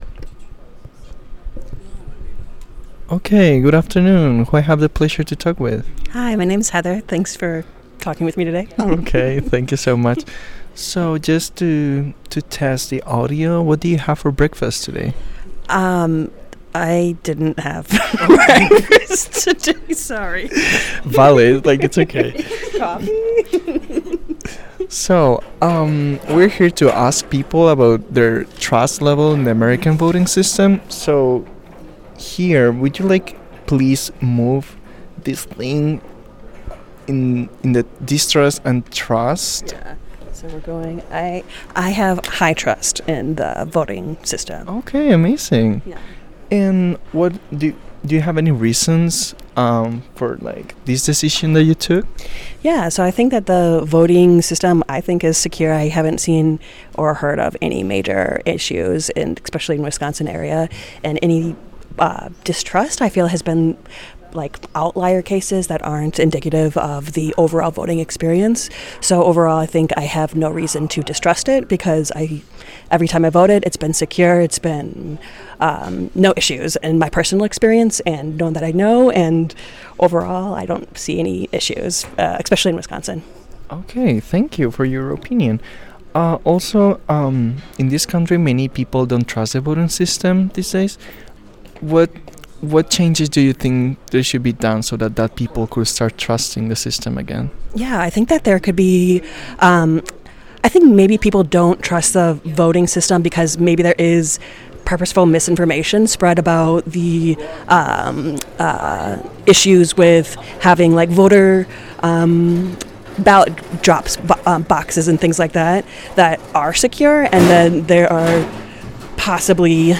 Location Milwaukee Central Library